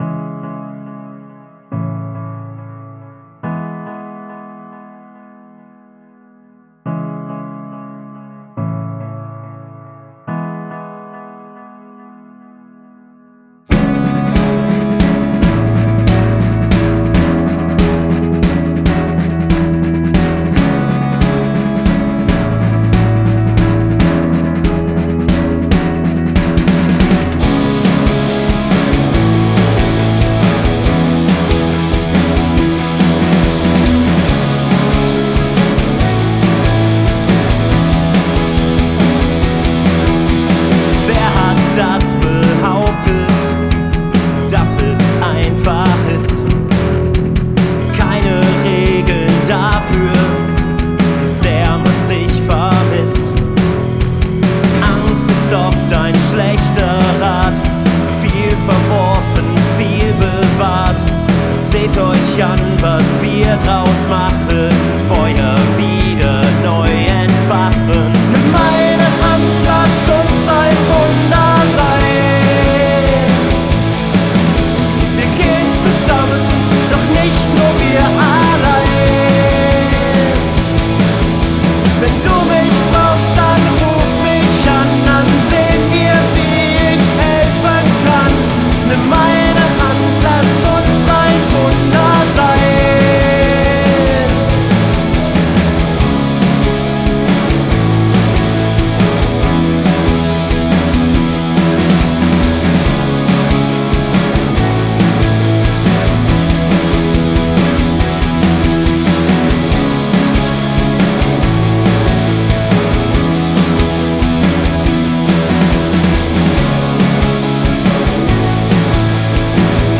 Indie-Rock / Wave - Ein Wunder
Bißchen melancholisch, alles bis auf Stufe 13 verzerrt, unendlich viel Delay auf allem.
Ich habe lange nicht mehr gemixt und irgendwie kommt mir das alles extrem mumpfelig vor.